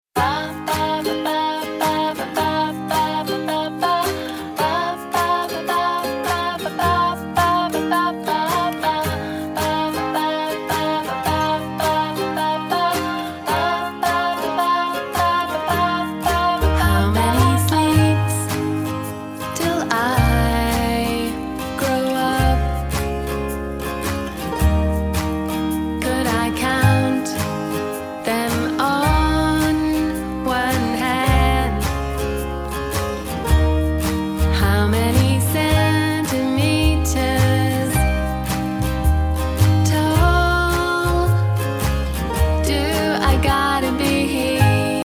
mindful and quietly exultant music